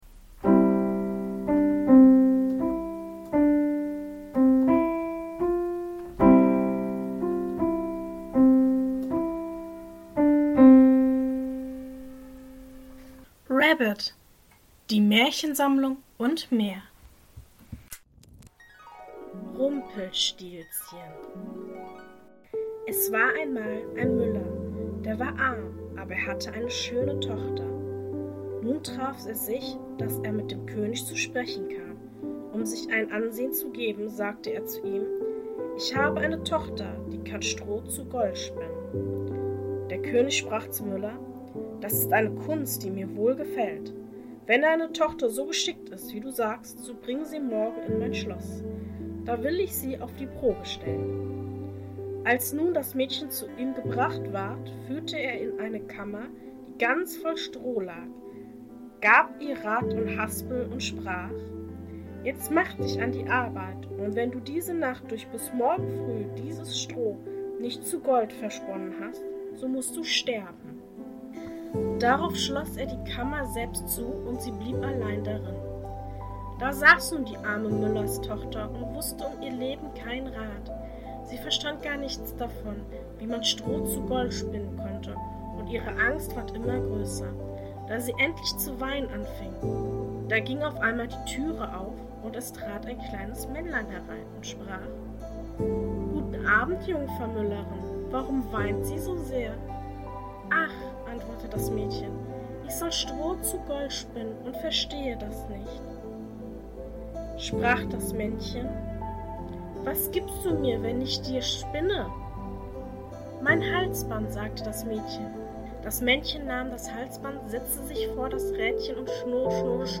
In der heutigen Folge lese ich Folgendes vor: 1. Rumpelstilzchen 2. Die sieben Raben Mehr